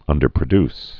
(ŭndər-prə-ds, -dys)